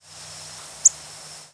squeaky: A piercing, high-pitched, slightly sweet overtone (e.g.,
Lark Sparrow).